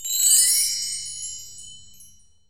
percussion 18.wav